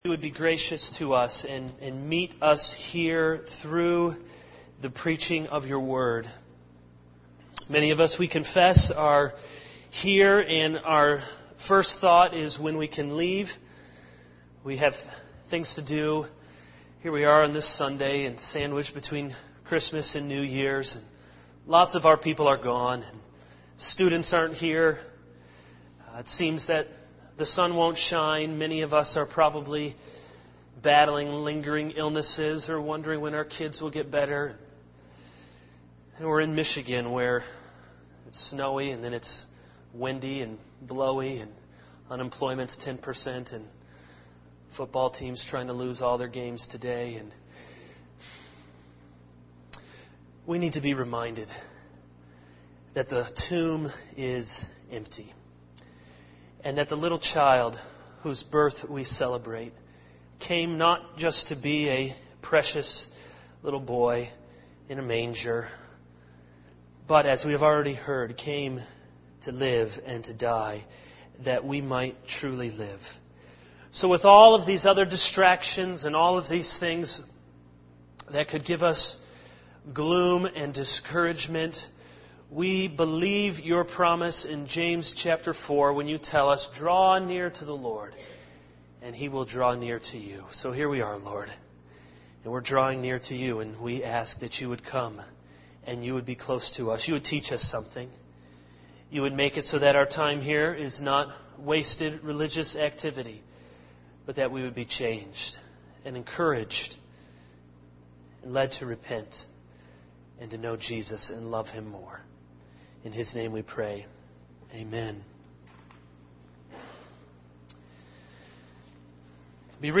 All Sermons How to (Almost) Ruin Your Relationships 0:00 / Download Copied!